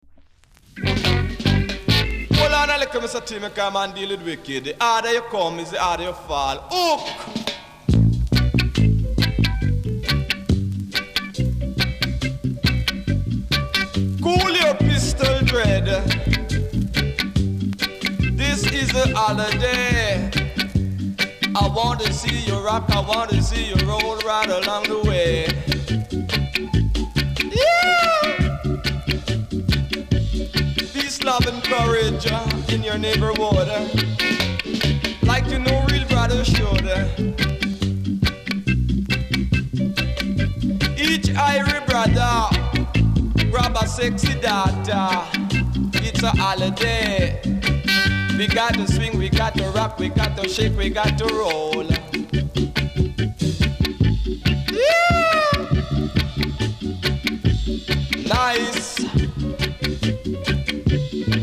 コメント NICE DEEJAY!!